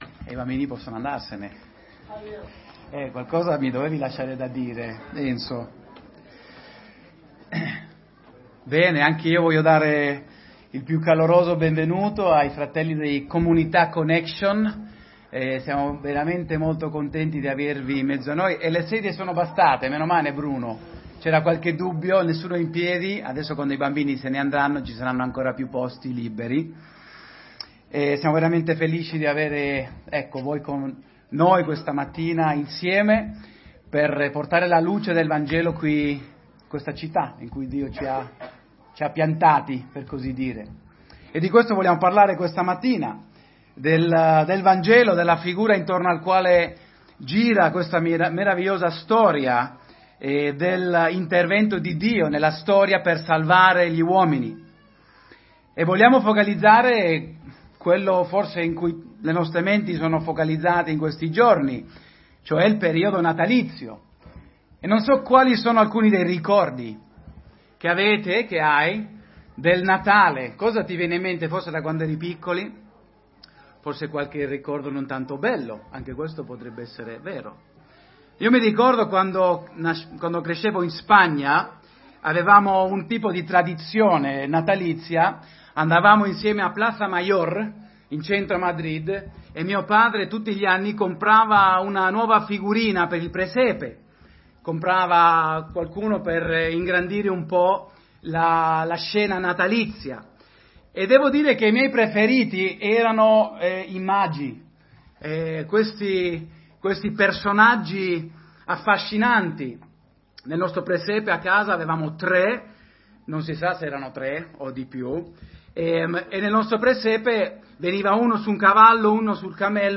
Tutti i sermoni Gesù messia 2 8 Dicembre